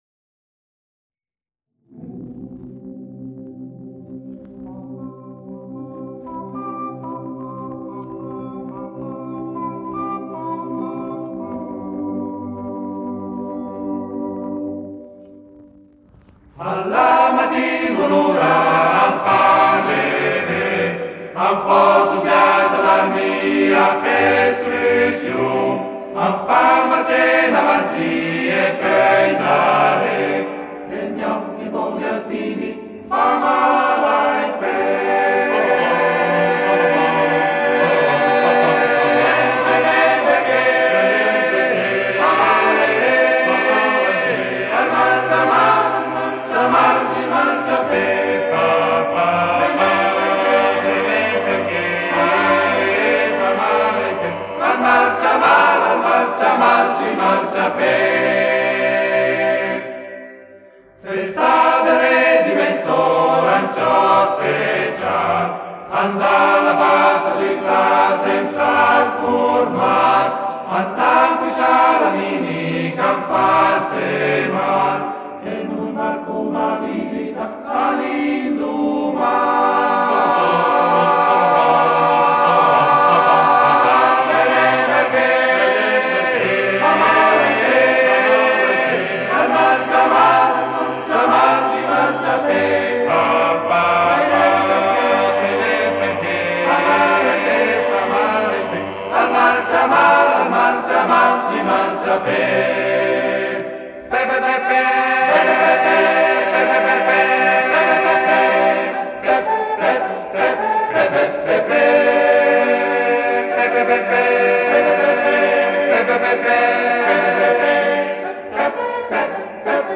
Esecutore: Coro Edelweiss